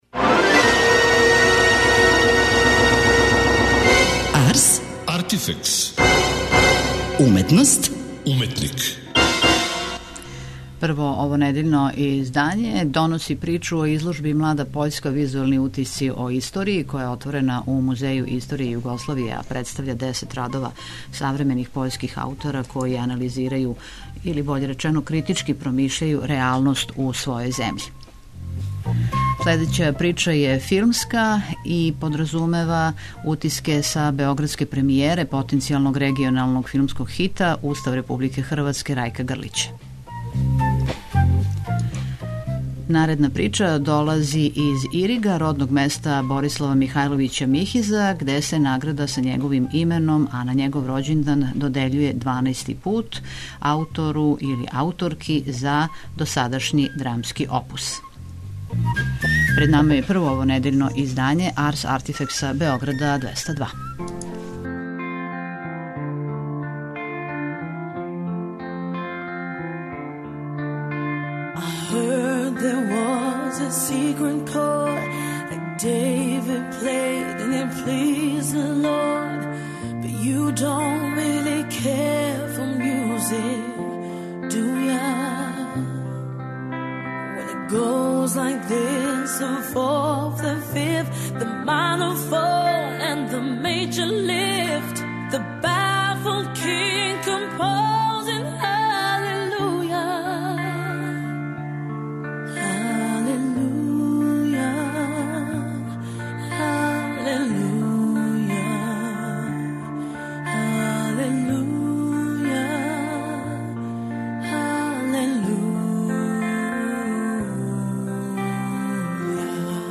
преузми : 26.78 MB Ars, Artifex Autor: Београд 202 Ars, artifex најављује, прати, коментарише ars/уметност и artifex/уметника. Брзо, кратко, критички - да будете у току.